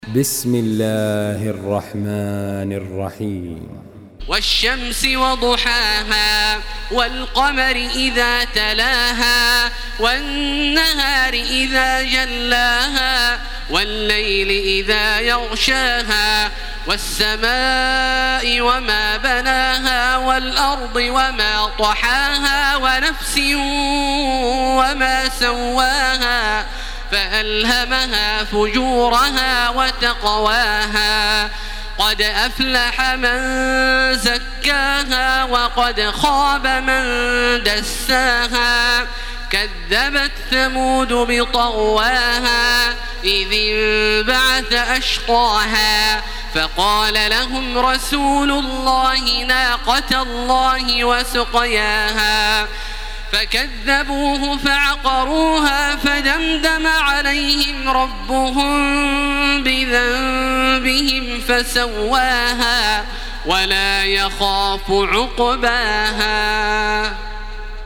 Surah Ash-Shams MP3 by Makkah Taraweeh 1434 in Hafs An Asim narration.
Murattal Hafs An Asim